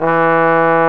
TROMBONE 207.wav